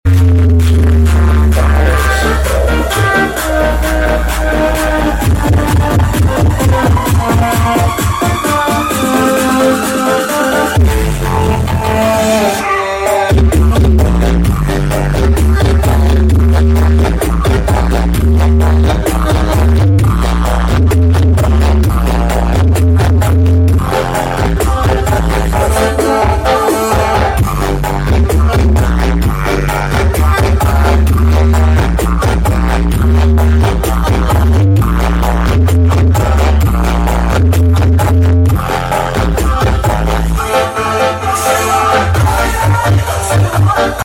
Pujon Karnaval Tawangsari 2024